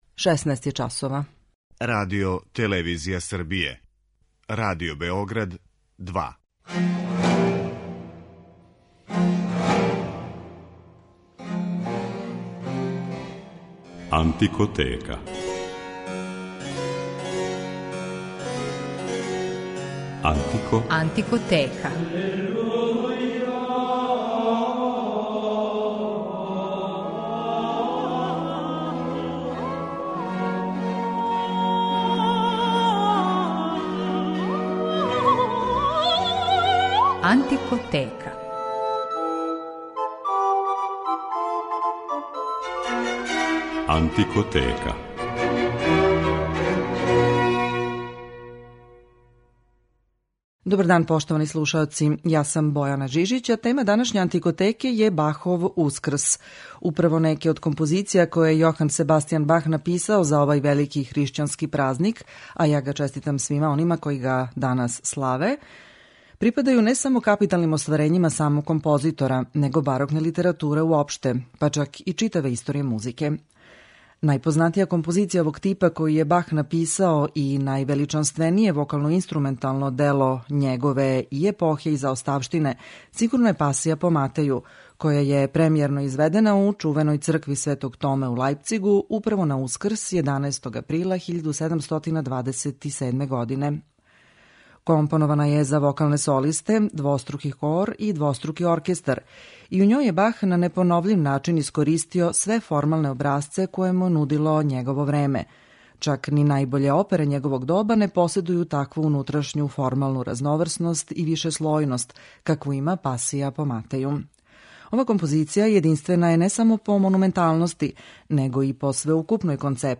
Њима је посвећена данашња Антикотека, чија је тема "Бахов Ускрс" и у њој ћете моћи да слушате најлепше фрагменте Бахове Пасије по Матеју и Пасије по Јовану.